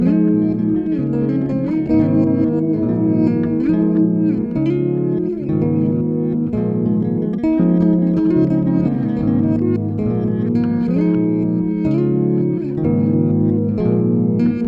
small repeatable loop (00:15).